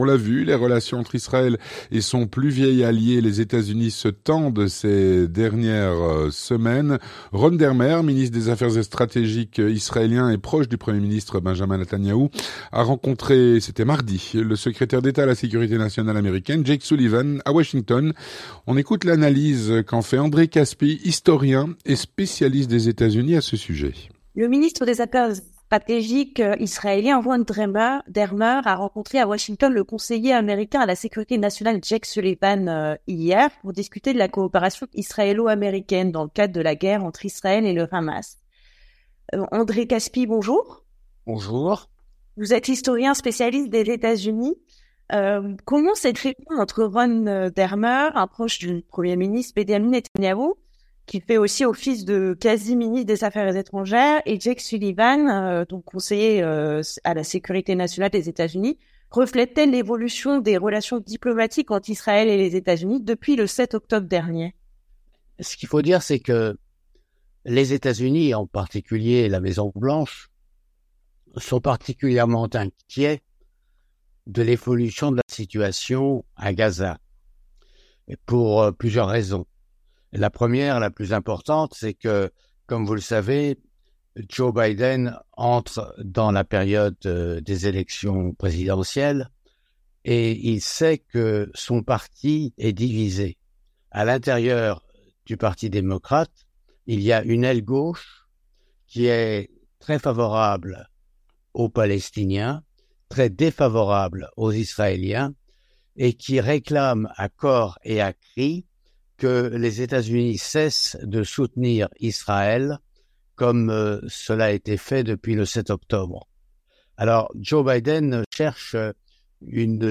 L'entretien du 18H - Les relations se tendent entre Israël et les États-Unis.
Avec André Kaspi, historien et spécialiste des Etats-Unis.